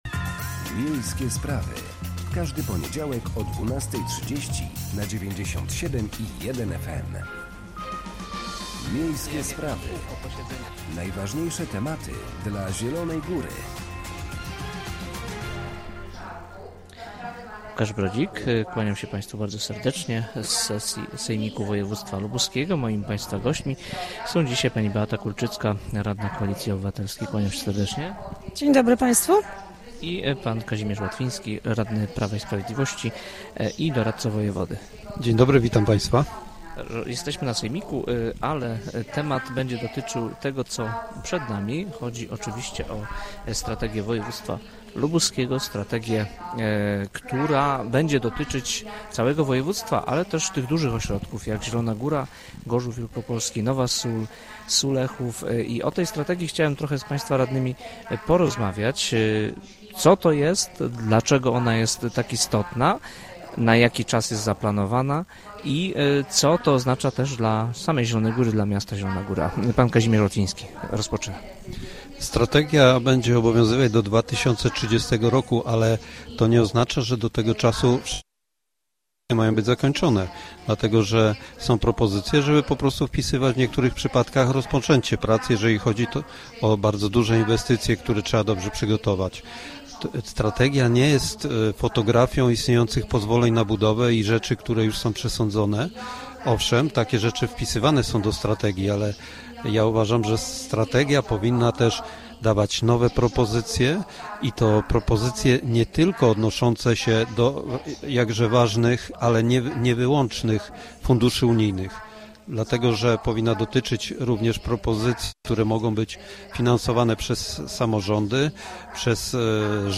Dziś podczas sesji sejmiku będę pytać radnych wojewódzkich o to, jakie zapisy powinny się znaleźć w strategii województwa lubuskiego, dotyczących przede wszystkim miasta Zielona Góra, czy znajdzie się tam miejsce dla reanimacji idei lubuskiego trójmiasta, czyli integracji Nowej Soli, Sulechowa i Zielonej Góry i czy będzie w niej mowa także o szybkiej kolei trójmiejskiej oraz jakie inne działania dotyczące naszego miasta zostaną wpisane w ten dokument.